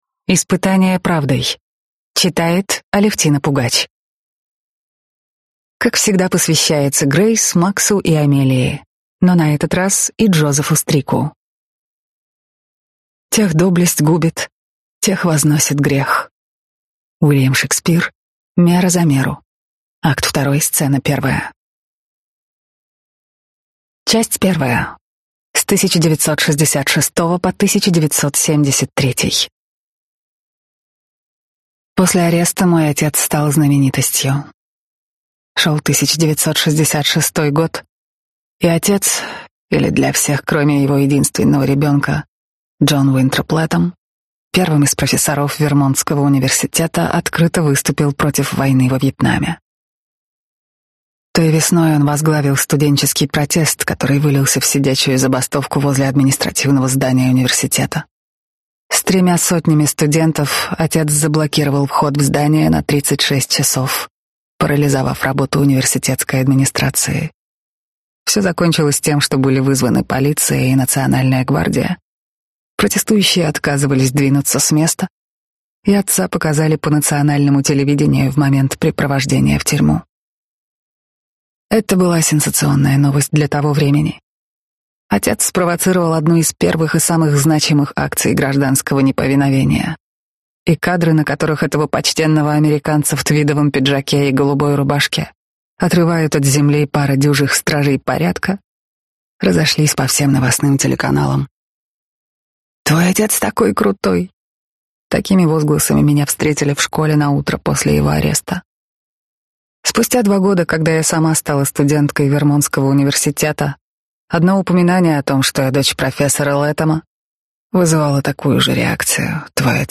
Аудиокнига Испытание правдой | Библиотека аудиокниг
Прослушать и бесплатно скачать фрагмент аудиокниги